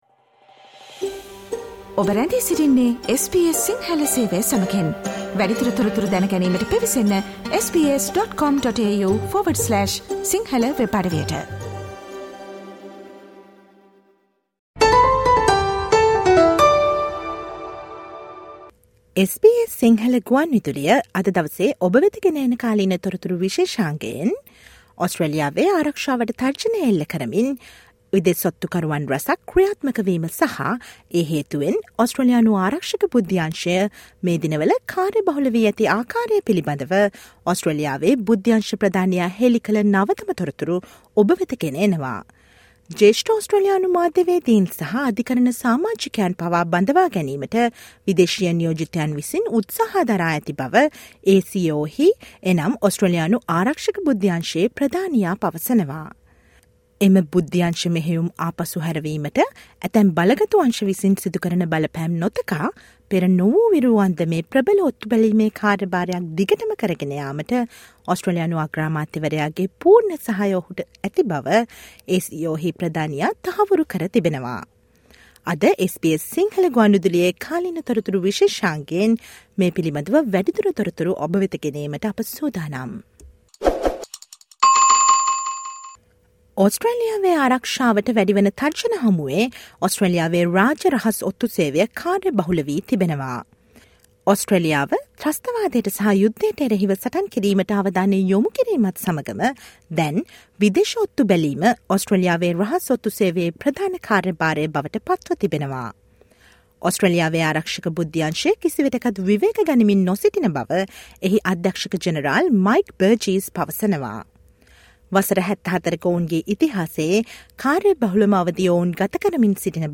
Listen to the SBS Sinhala radio current affair feature on annual national security updates from the ASIO Director-General outlining a number of operations to protect Australia's national security.